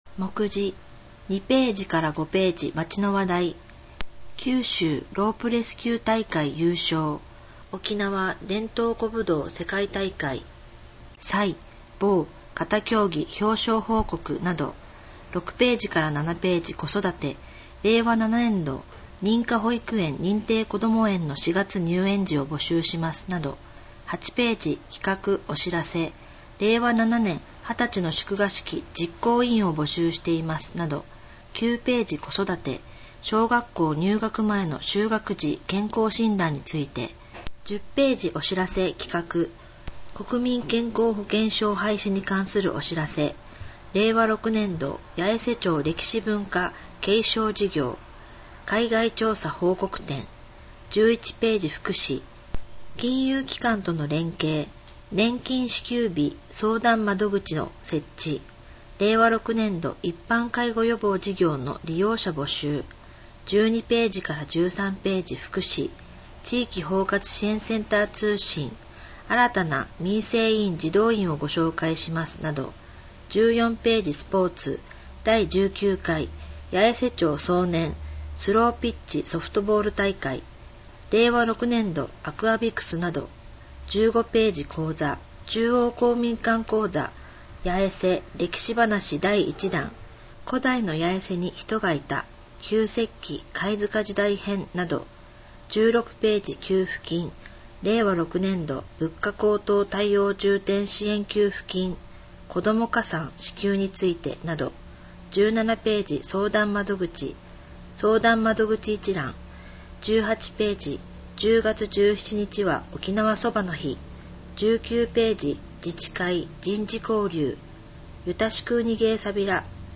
声の「広報やえせ」　令和6年10月号226号